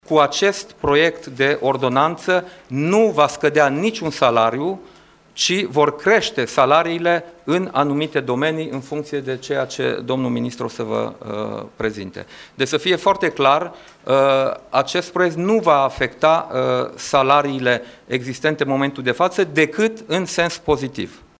Premierul a mai ținut să sublinieze că prin acest proiect niciun salariu nu va scădea și că ca acest proiect sa fie punctul de pornire pentru ceea ce ar trebui sa insemne eficienta in sistemu public: